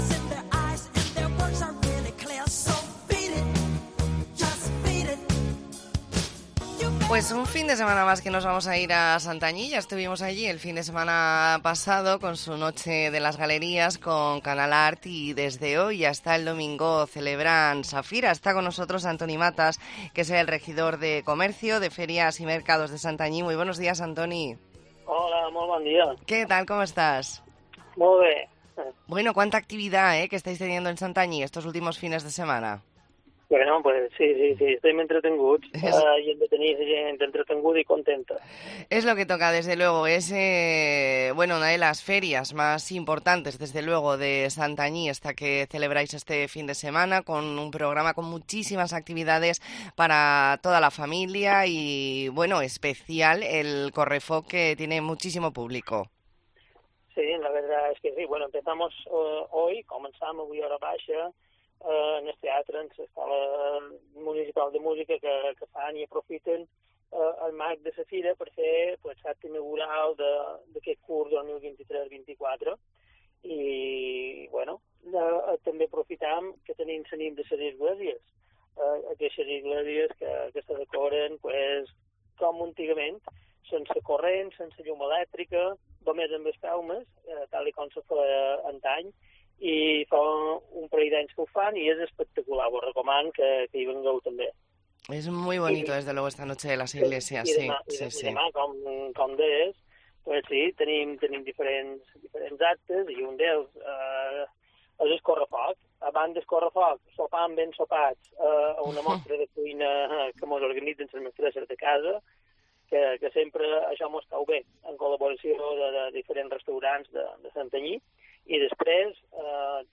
Hablamos con Antoni Matas, regidor de Comerç, Fires i Mercats de Santanyí. Entrevista en La Mañana en COPE Más Mallorca, viernes 20 de octubre de 2023.